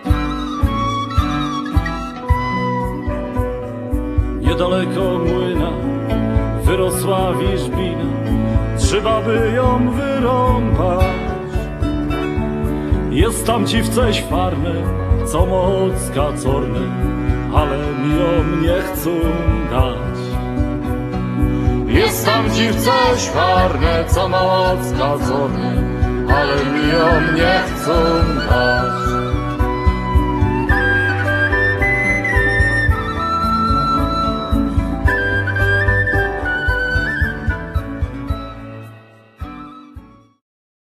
wiolonczela, chórki
mandola, dutar, gitara
akordeon